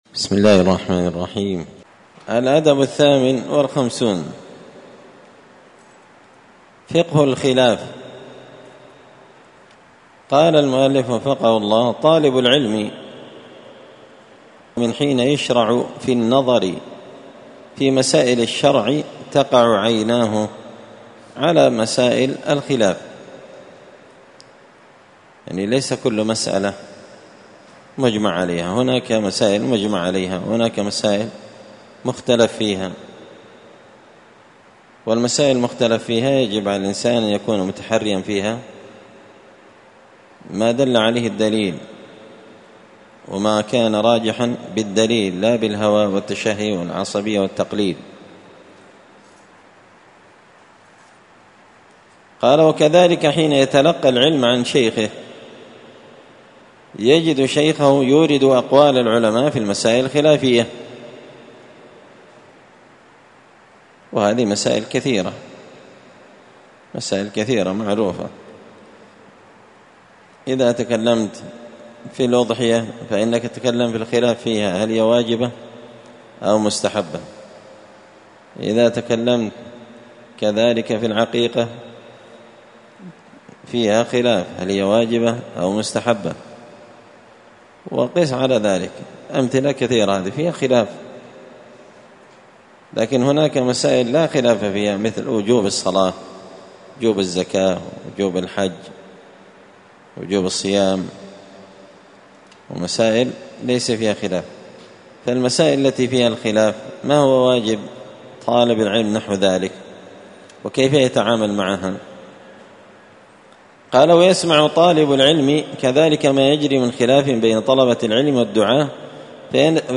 الأثنين 15 ذو الحجة 1444 هــــ | الدروس، النبذ في آداب طالب العلم، دروس الآداب | شارك بتعليقك | 9 المشاهدات
مسجد الفرقان قشن_المهرة_اليمن